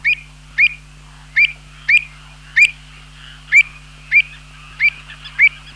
Pied Avocet
Recurvirostra avosetta
Pied-Avocet.mp3